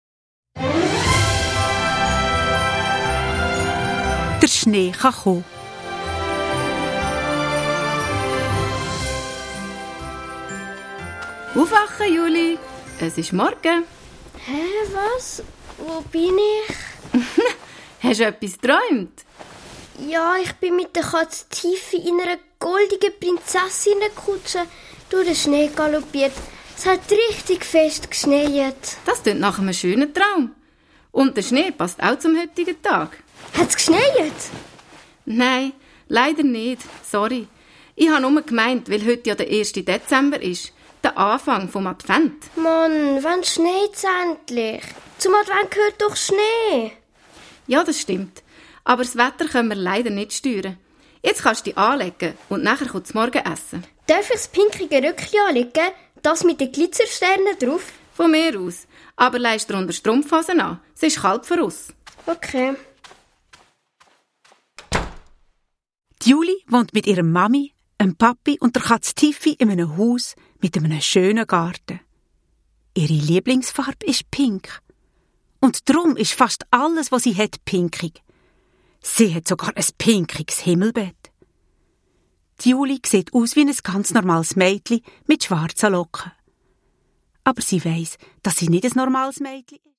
Hörspiel-Adventskalender